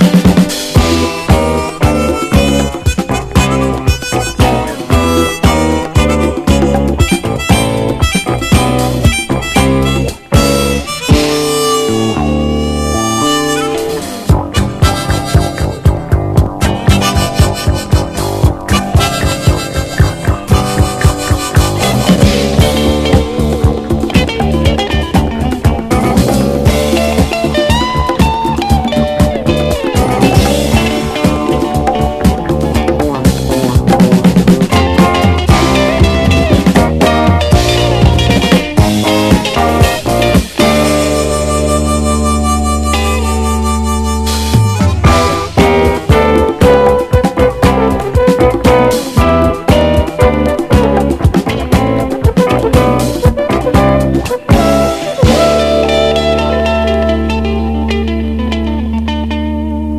JAPANESE CLUB / BREAKBEATS
ド・ファンキー・カットアップ・ブレイクス